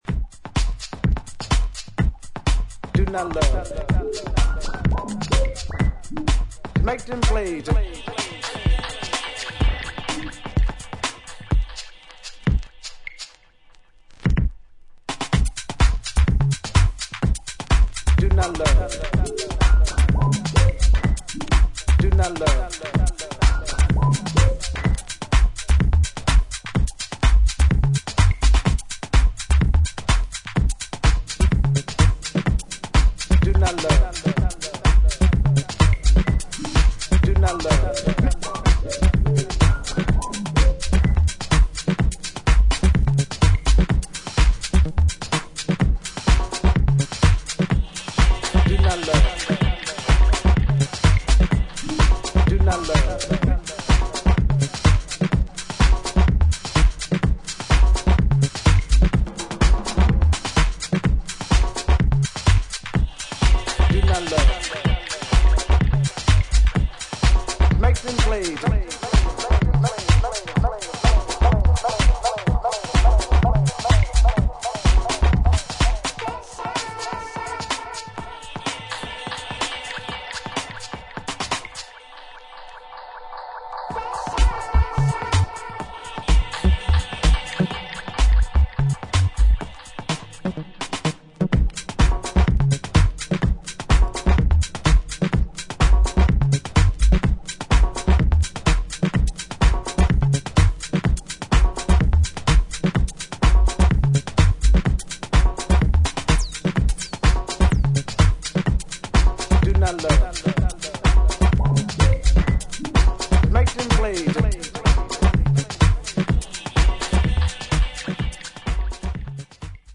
跳ねたグルーヴにシカゴ的シンセ、ダブ・ベースやラガ的なヴォイス・サンプルが展開する